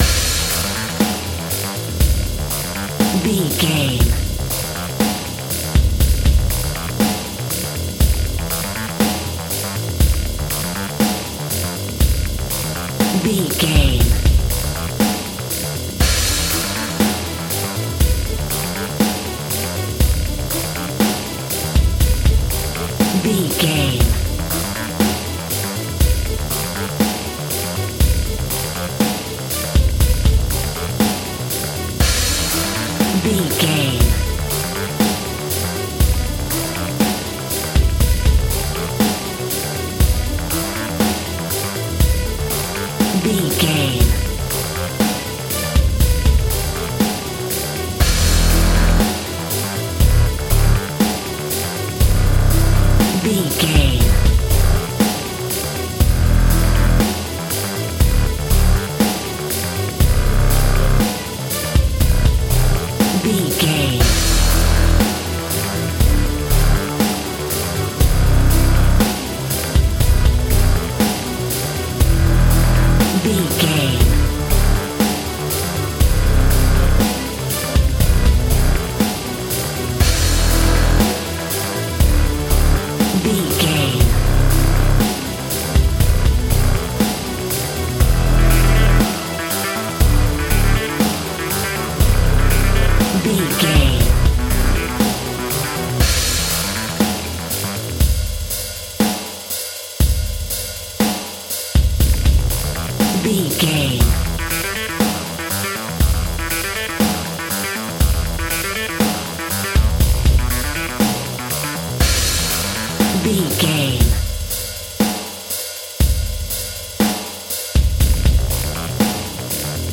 Epic / Action
Fast paced
Aeolian/Minor
intense
high tech
futuristic
energetic
driving
repetitive
dark
strings
drums
drum machine
synthesiser
electronic
synth lead
synth bass